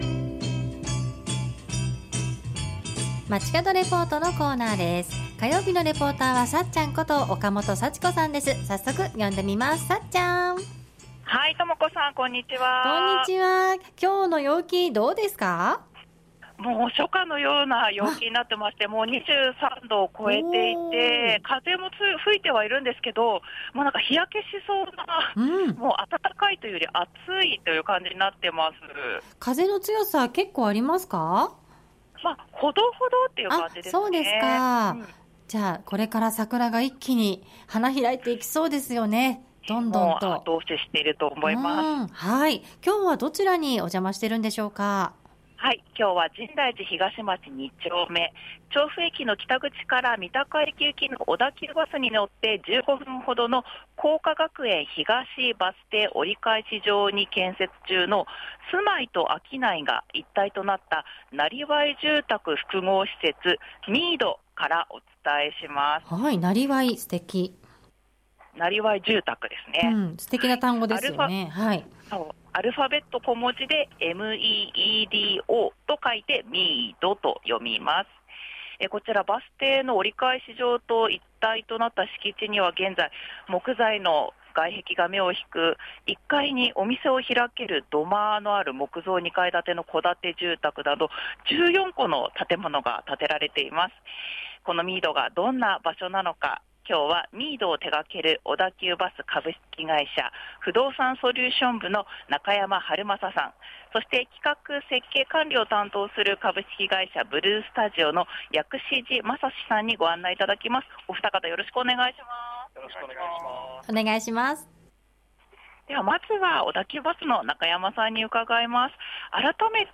中継は深大寺東町2丁目。